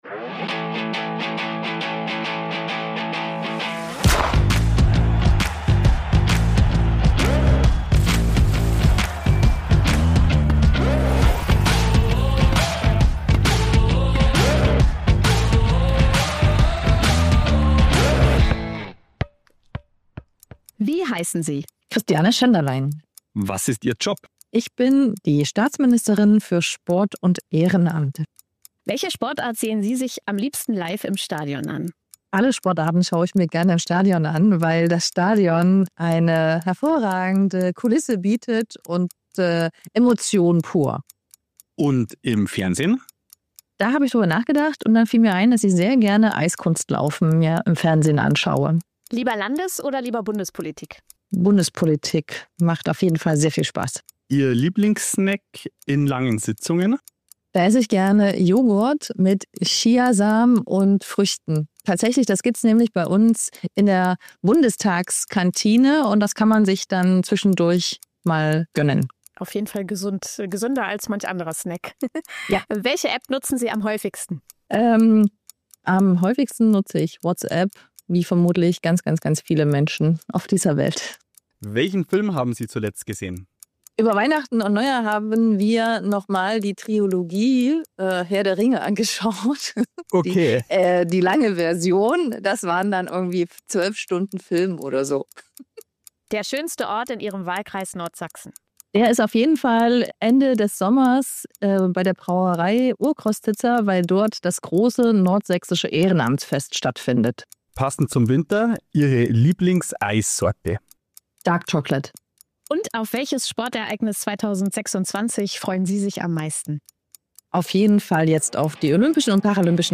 In dieser Folge zu Gast: Dr. Christiane Schenderlein, die Staatsministerin für Sport und Ehrenamt.